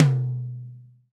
TOM TOM 90.wav